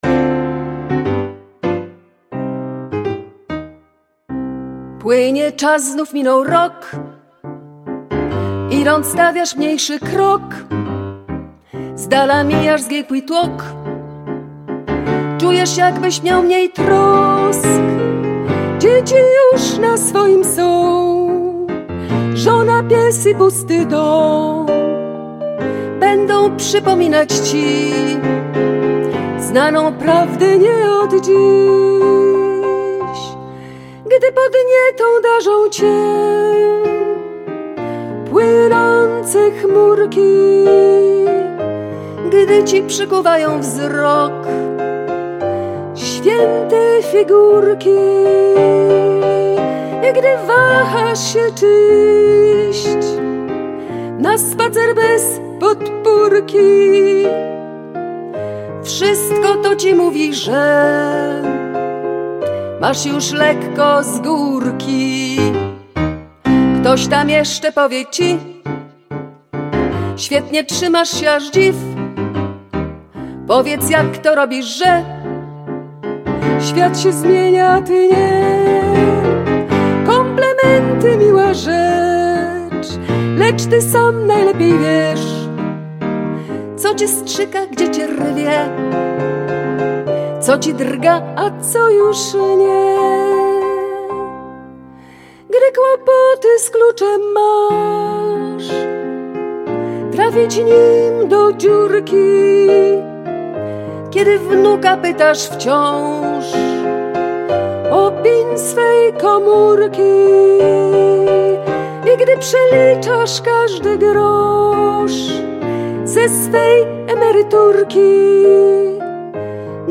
kabaretowej piosenki "Płynie czas